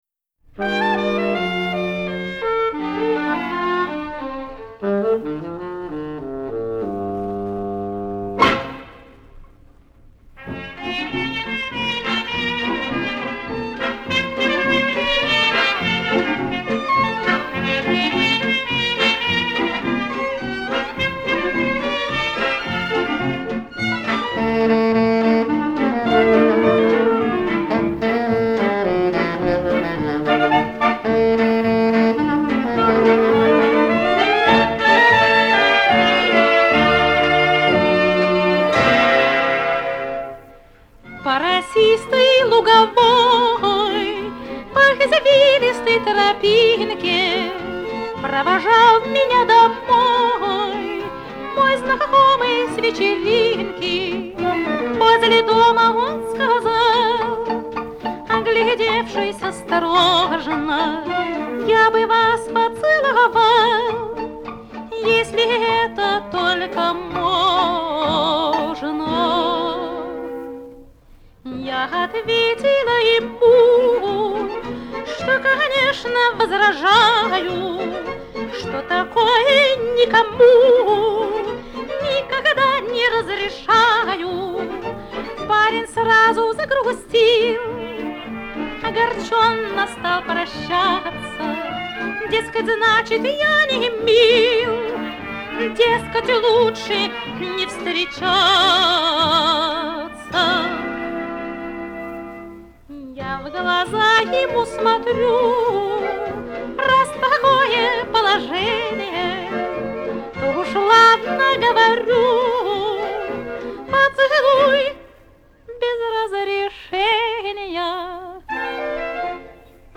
Какой чудесный чистый голос!